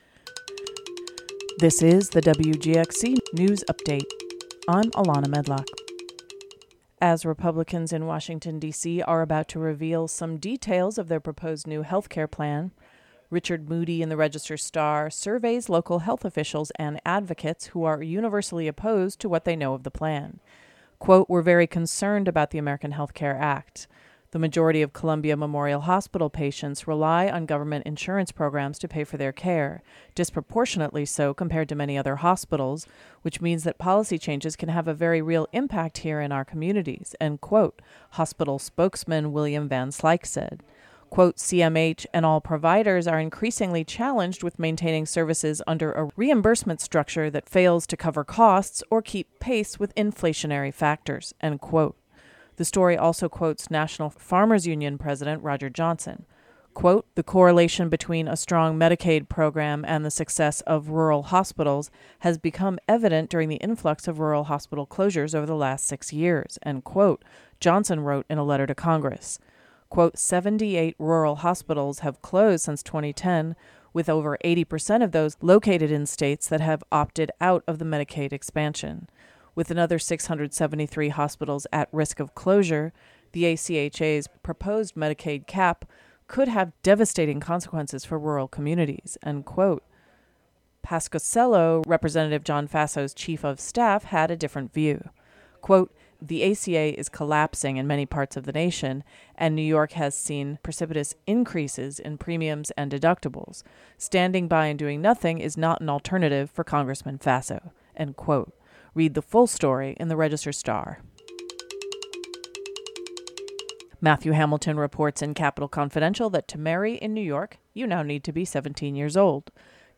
WGXC Local News Audio Link